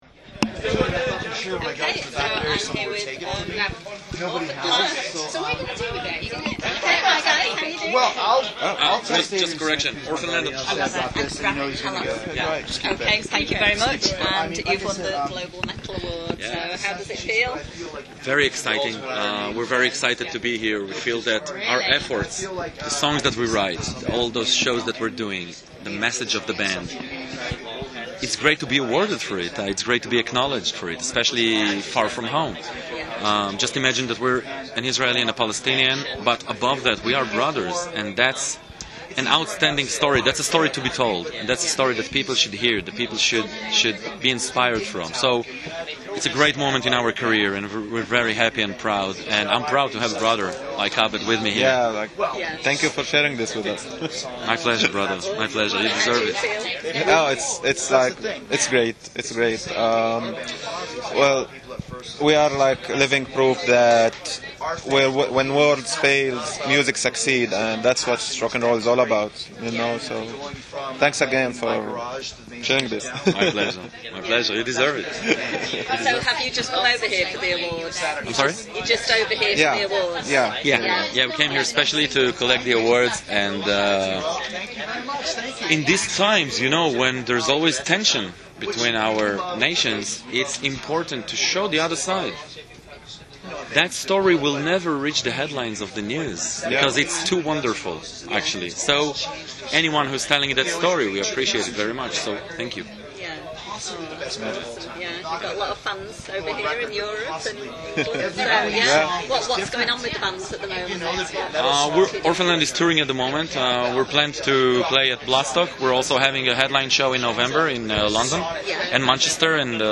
Interview With ORPHANED LAND, KHALAS: Winners Of ‘Global Metal Act’ Golden God Award; “Just imagine, we’re an Israeli and a Palestinian, but above that we’re brothers.”